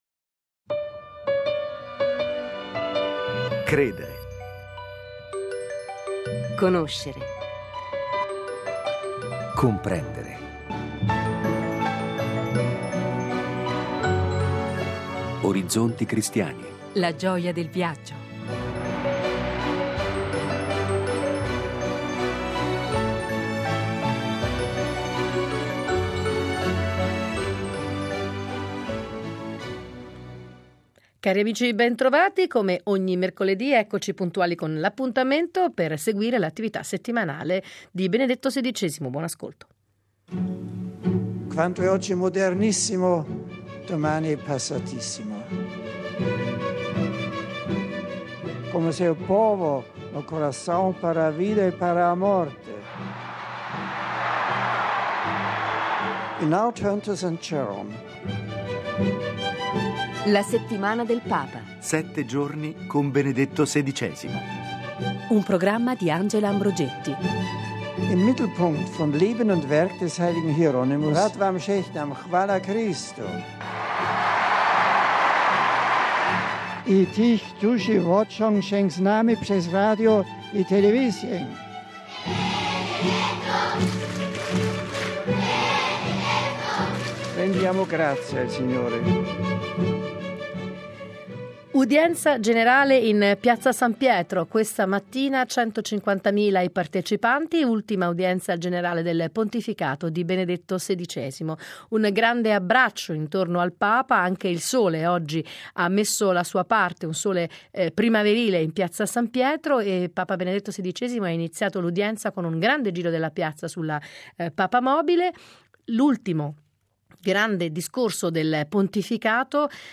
mercoledì 27 febbraio La settimana del Papa è da anni un programma di riferimento per chi vuole riascoltare ed approfondire i temi delle catechesi del mercoledì. Nell'appuntamento di oggi potrete riascoltare l'ultimo grande discorso del pontificato di Papa Benedetto XVI , pronunciato questa mattina in Piazza San Pietro, alla presenza di oltre 150 mila persone.